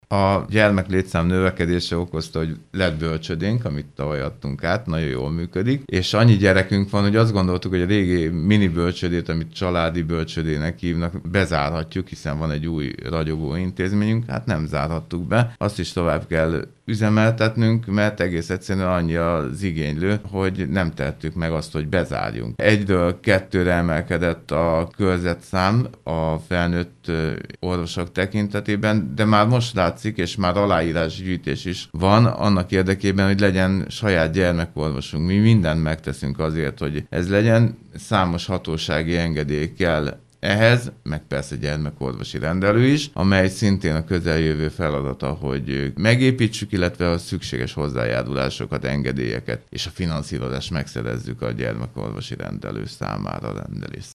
Most azonban a gyermeklétszám az állandó gyermekorvos jelenlétét igényli, ennek feltételeit igyekszik megteremteni az önkormányzat. Dr. Riebl Antal polgármestert hallják.